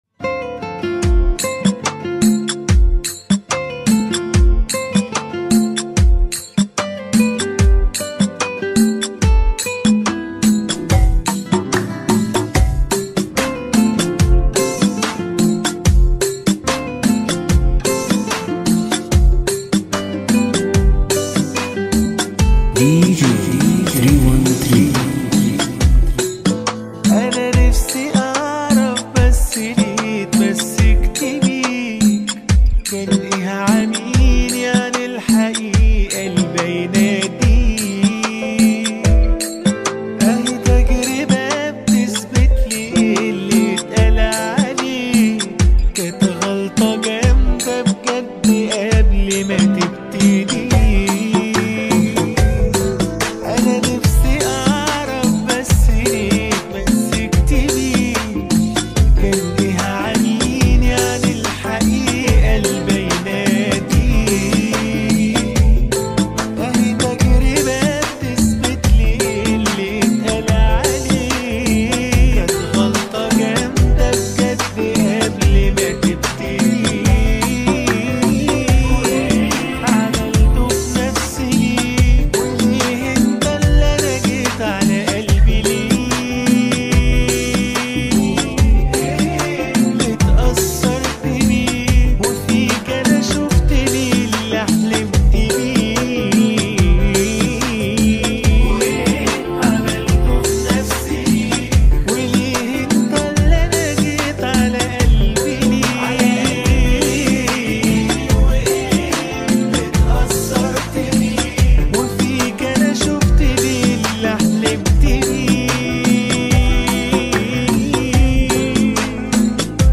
ريمكس